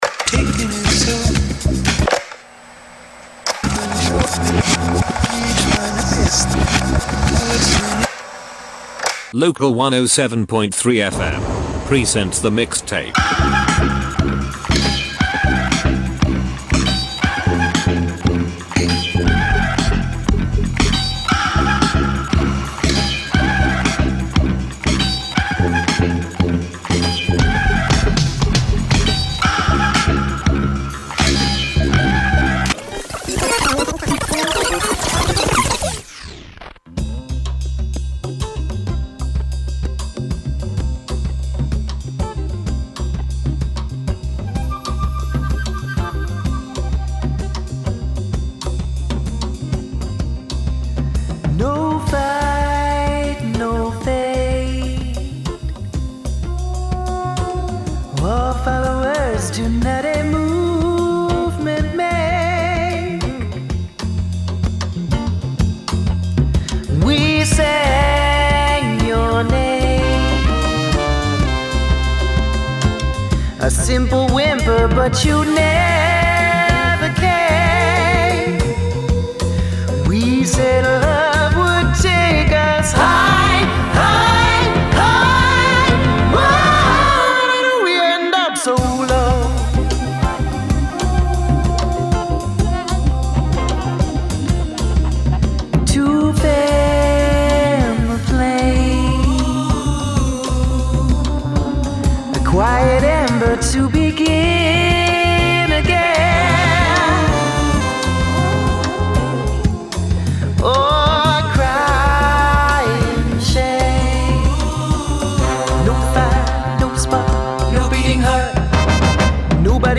S12E11: Weekly all-Canadian community radio music potpourri